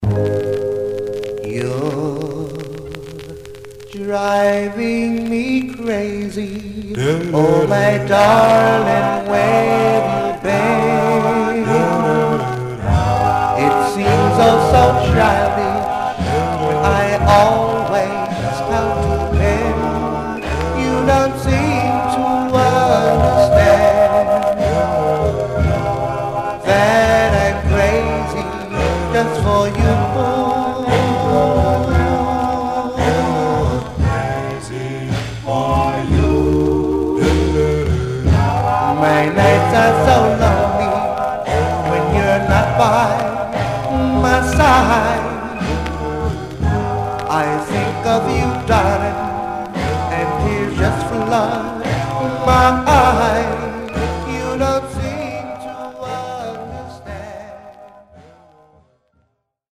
Surface noise/wear
Mono
Male Black Group